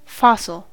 fossil: Wikimedia Commons US English Pronunciations
En-us-fossil.WAV